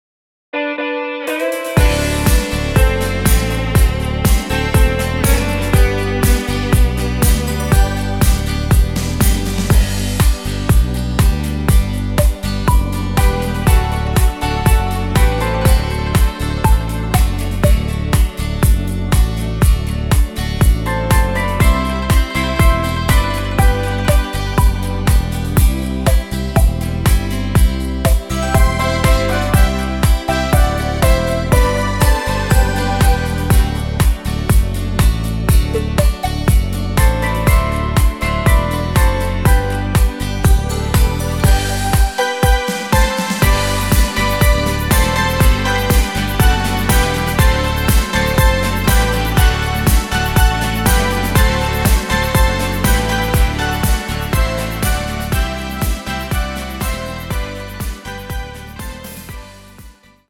super Rhythmus